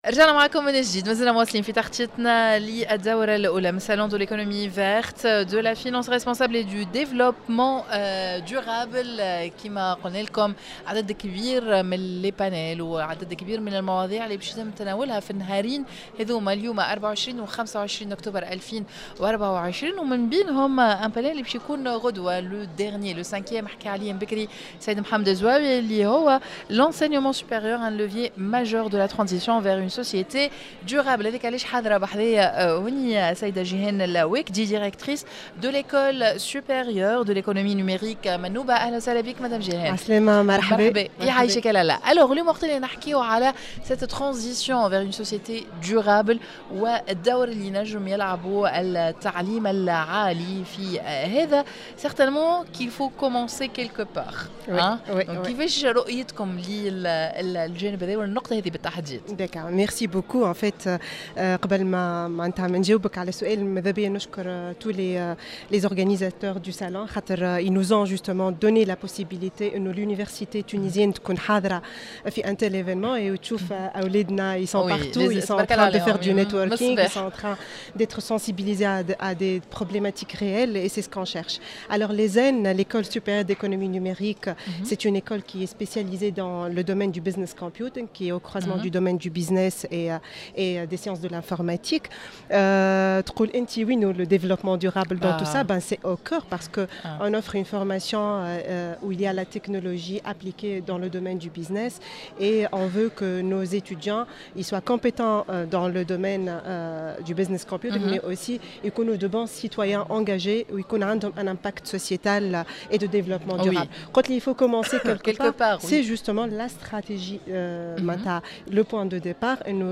dans un plateau spécial en direct